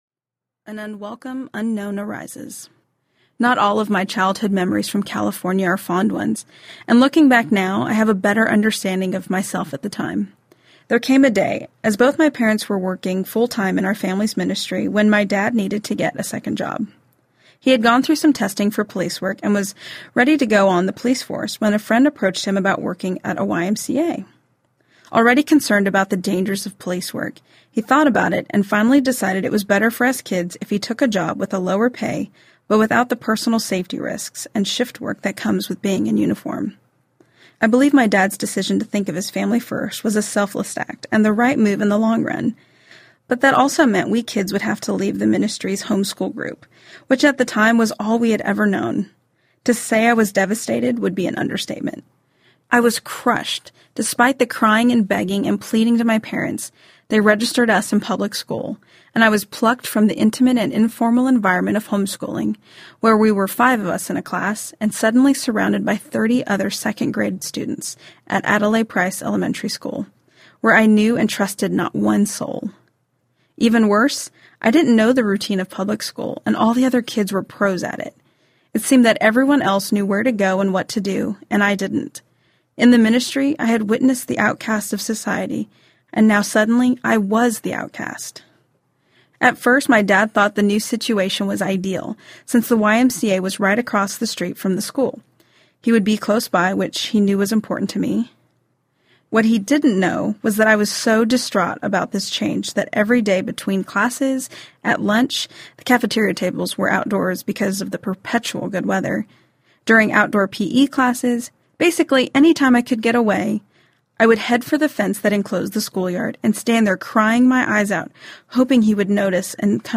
Becoming Fearless Audiobook
Narrator